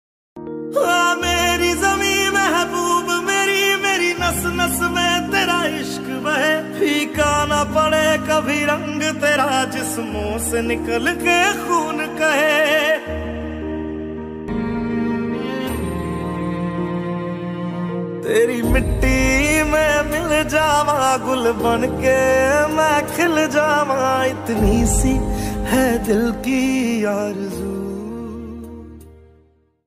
ringtone of punjabi songs
Best sad Ringtone